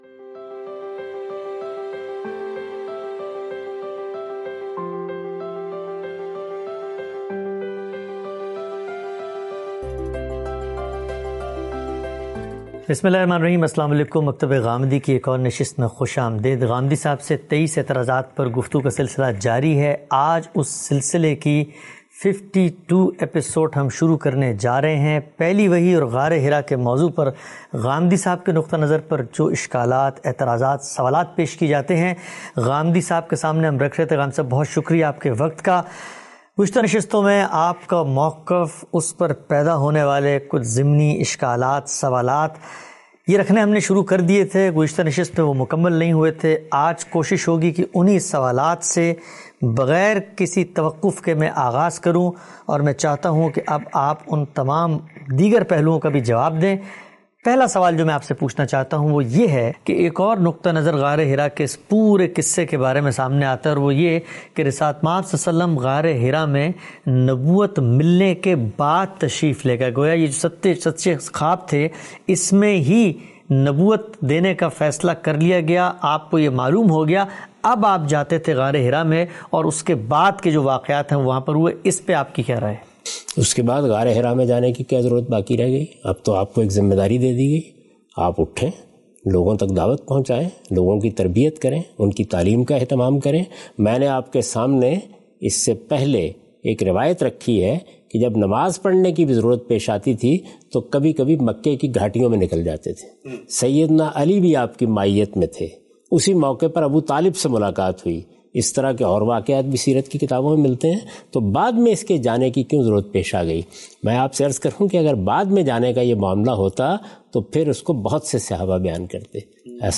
In this video, Mr Ghamidi answers questions